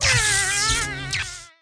Npc Catzap Sound Effect
npc-catzap-1.mp3